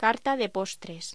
Locución: Carta de postres
voz